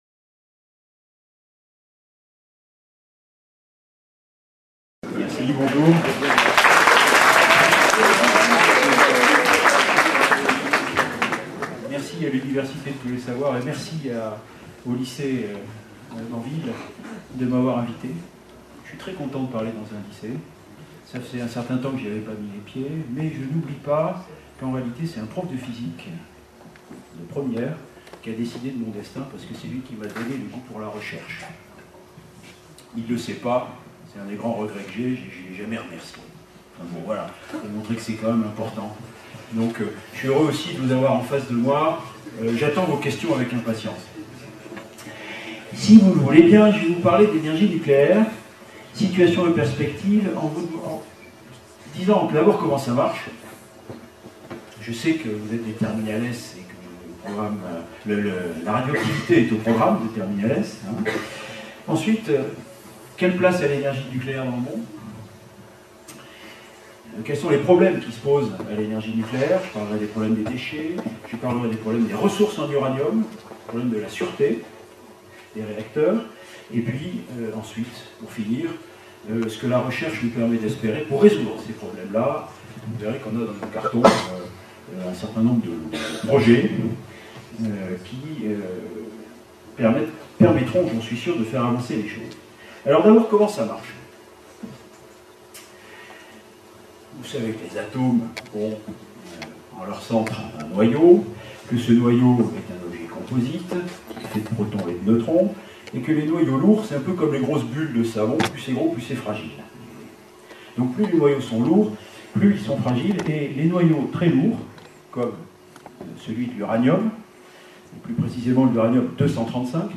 Lycée Théodore de Banville (03017 Moulins cedex)